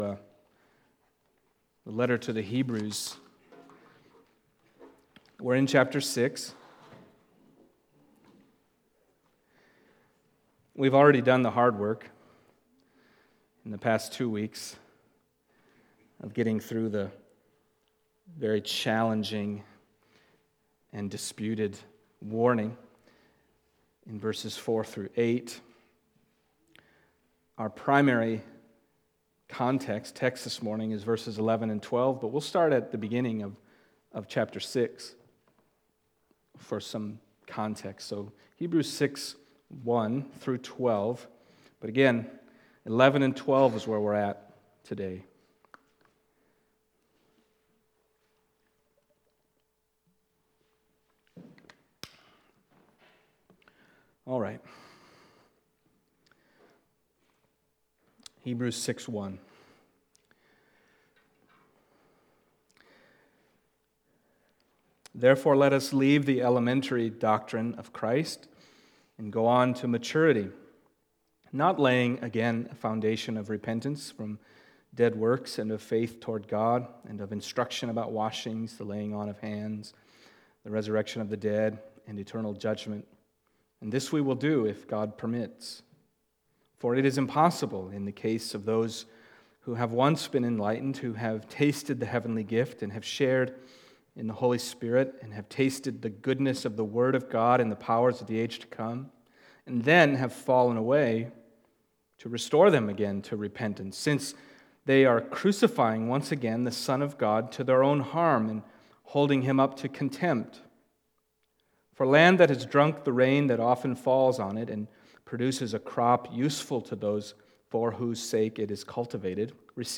Passage: Hebrews 6:4-12 Service Type: Sunday Morning